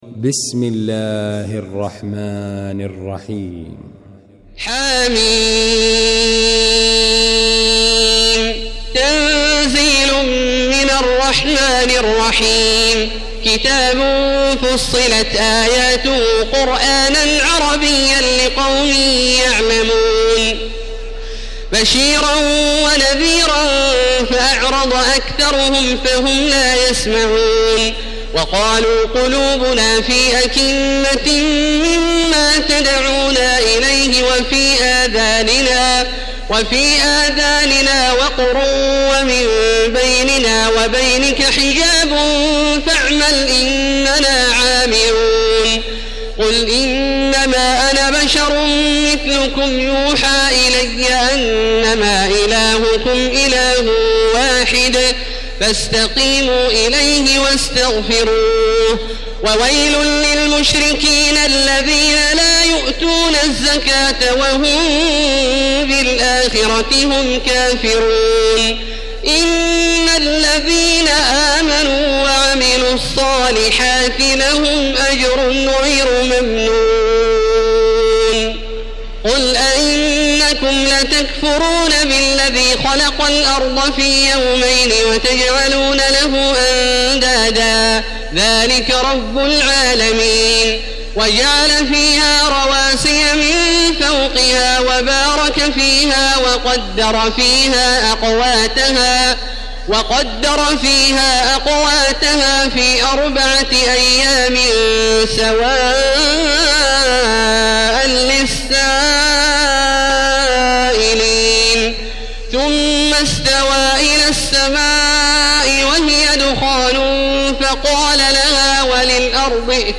المكان: المسجد الحرام الشيخ: فضيلة الشيخ عبدالله الجهني فضيلة الشيخ عبدالله الجهني فصلت